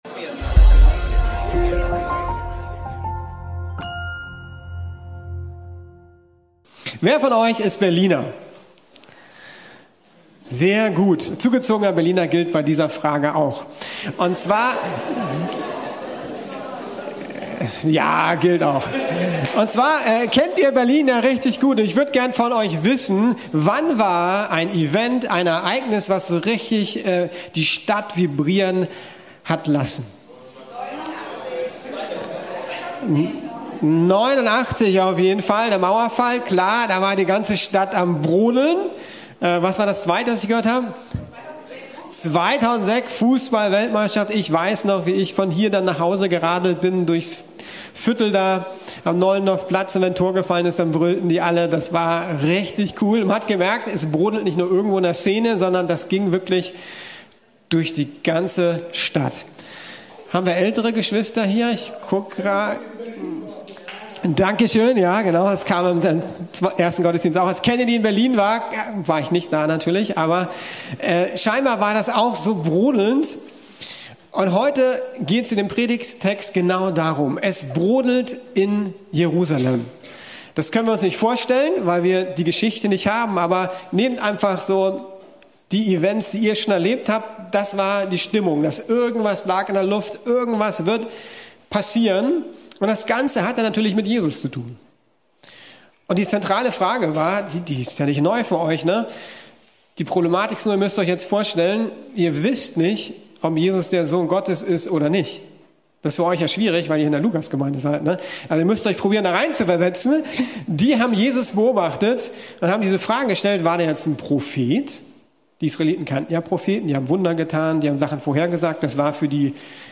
Ein ungewöhnlicher König ~ Predigten der LUKAS GEMEINDE Podcast